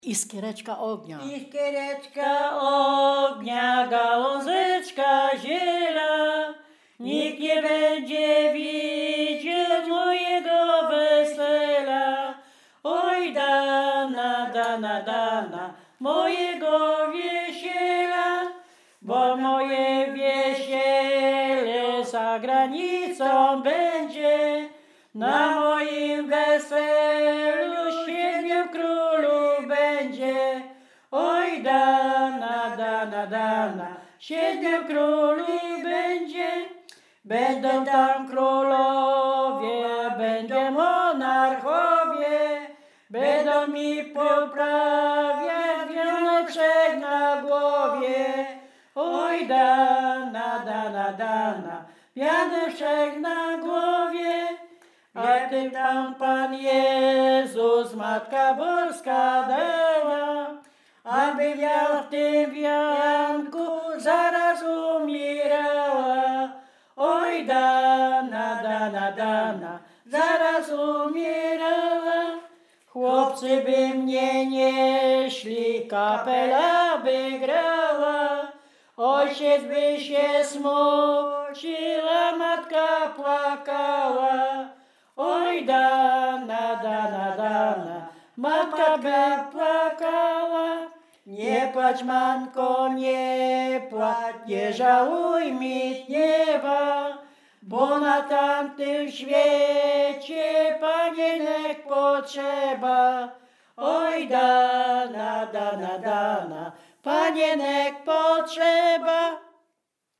województwo dolnośląskie, powiat lwówecki, gmina Lwówek Śląski, wieś Zbylutów
Weselna
liryczne miłosne weselne wesele